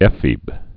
(ĕfēb, ĭ-fēb) also e·phe·bus (ĭ-fēbəs)